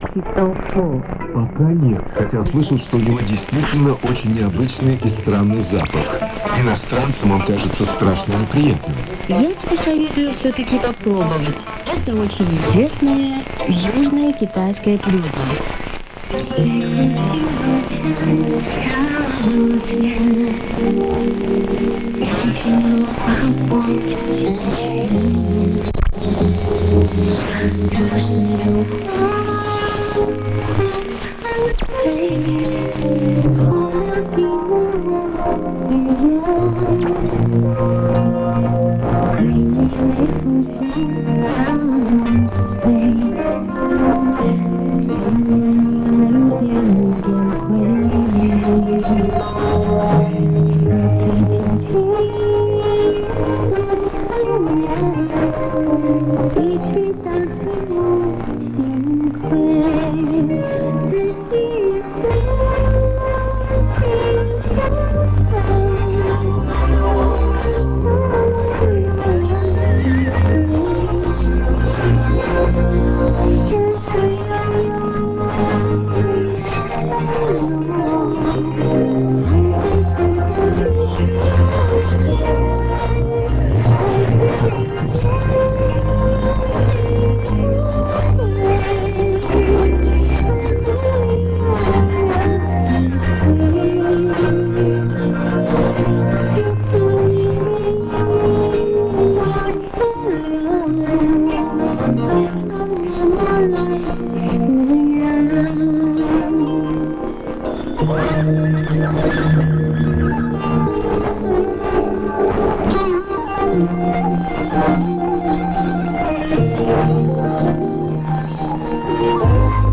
LOC: CHINA, URUMQI HUTUBI, 44.08 N 86.53 E